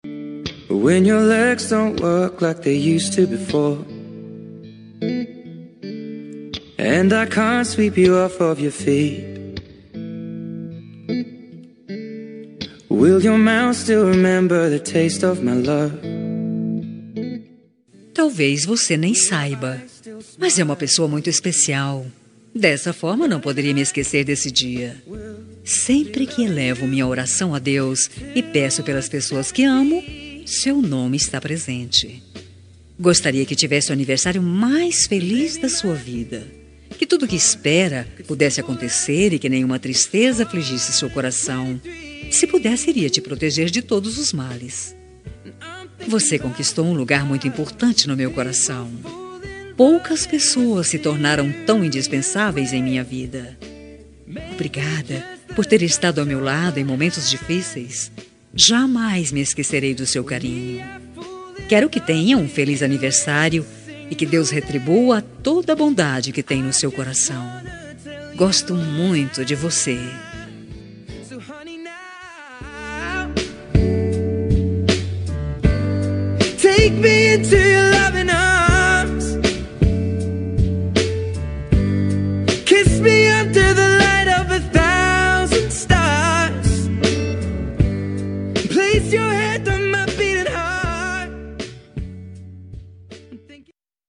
Aniversário de Pessoa Especial – Voz Feminina – Cód: 4189